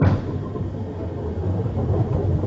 Bowlingroll-MONO (better).wav